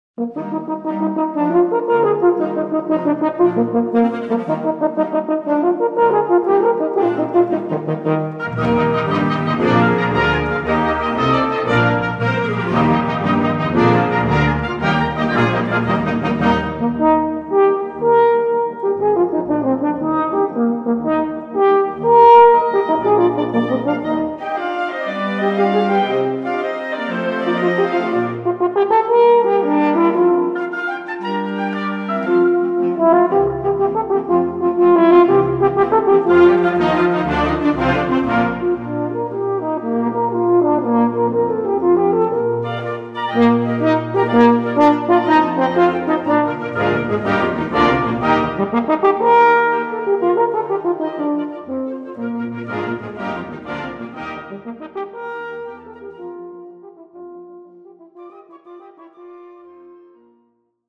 Gattung: Horn in F Solo
Besetzung: Blasorchester